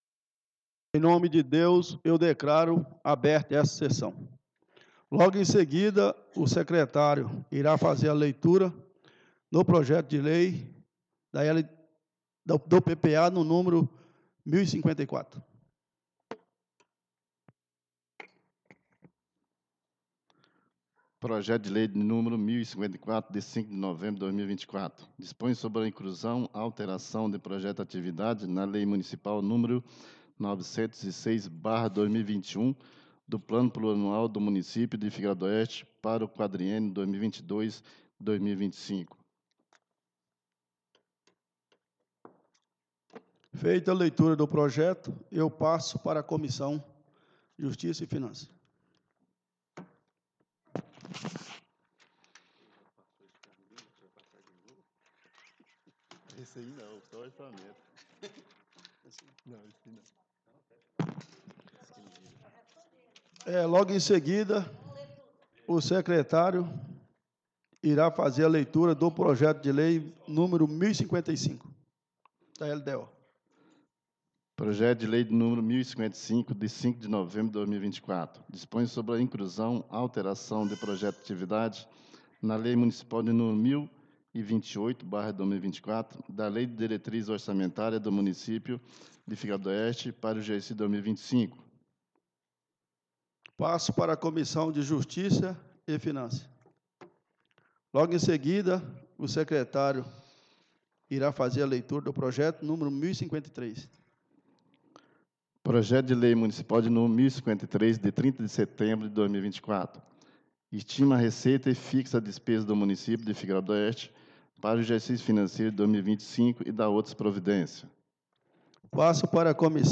19° SESSÃO ORDINÁRIA DO DIA 30 DE DEZEMBRO DE 2024